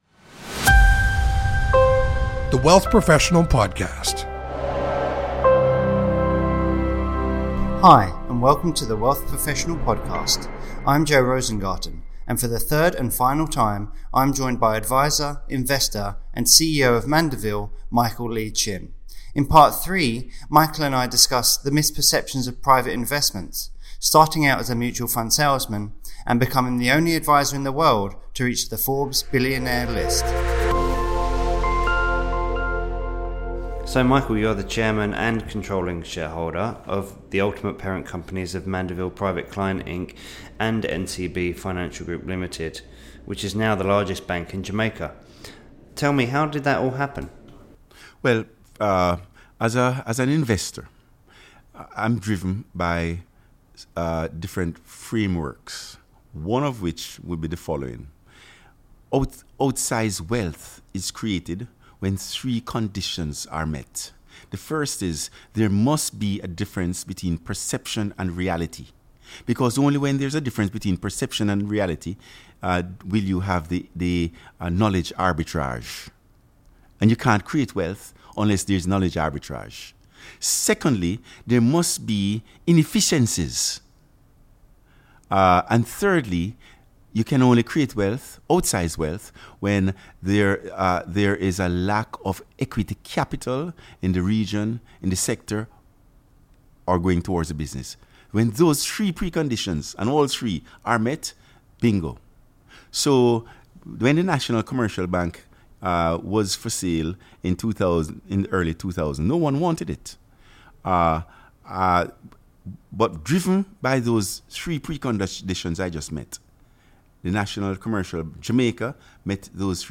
In the first of a special three-part series, WP sits down with renowned advisor, investor and CEO of financial planning firm Mandeville Private Client Inc, Michael Lee-Chin. To listen to the podcast visit: PART 1 – Michael Lee-Chin on how advisors can embrace the future PART 2 – Michael Lee-Chin on what advisors should look for in a brokerage in 2019 PART 3 – Michael Lee-Chin on private investment myths and conditions for wealth creation